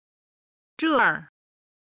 ただ軽音部分の音源がないので、元々の単語の四声にしています。
音源には本来の四声の数字を表記(軽声は5と表記)、音と目で音源の四声が分かるようにしています。